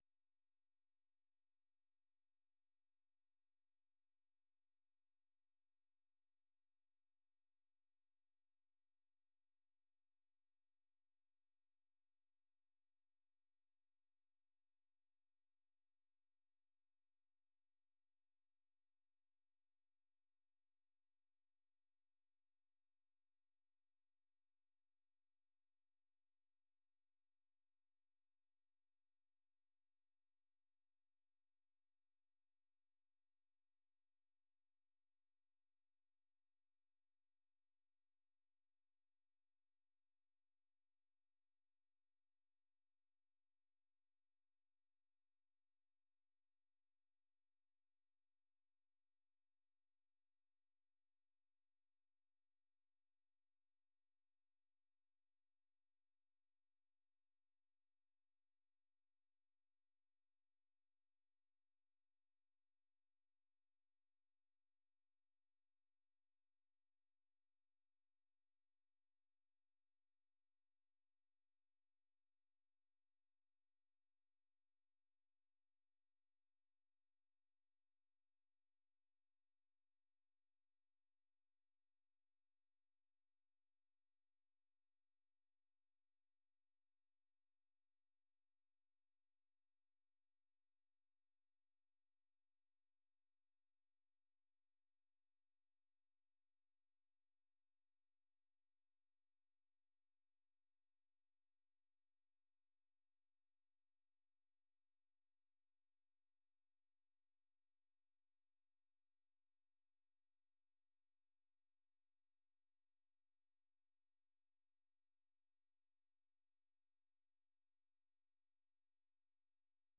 ဗွီအိုအေမြန်မာပိုင်းရဲ့ ညပိုင်း မြန်မာစံတော်ချိန် ၉ နာရီမှ ၁၀နာရီအထိ ရေဒီယိုအစီအစဉ်ကို ရေဒီယိုကနေ ထုတ်လွှင့်ချိန်နဲ့ တပြိုင်နက်ထဲမှာပဲ Facebook နဲ့ Youtube ကနေလည်း တိုက်ရိုက် ထုတ်လွှင့်ပေးနေပါတယ်။